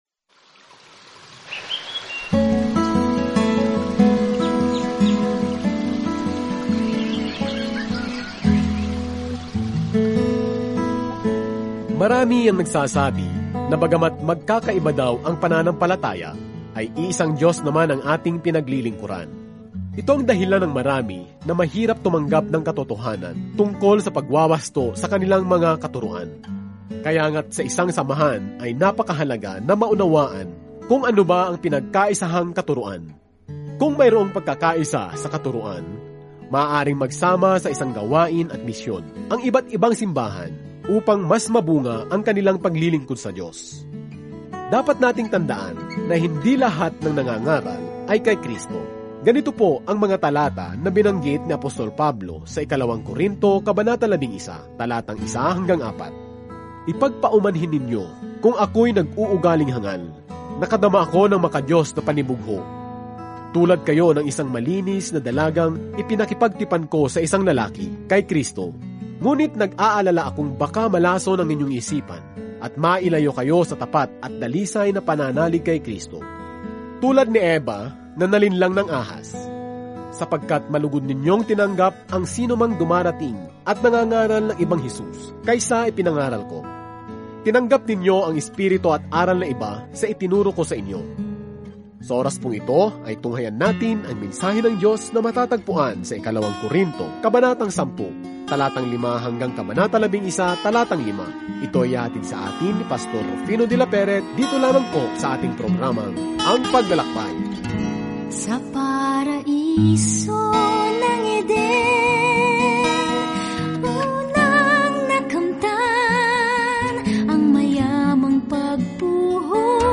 Banal na Kasulatan 2 Mga Taga-Corinto 10 2 Mga Taga-Corinto 11:1-5 Araw 16 Umpisahan ang Gabay na Ito Araw 18 Tungkol sa Gabay na ito Ang kagalakan ng mga relasyon sa loob ng katawan ni Kristo ay naka-highlight sa ikalawang liham sa mga taga-Corinto habang nakikinig ka sa audio study at nagbabasa ng mga piling talata mula sa salita ng Diyos.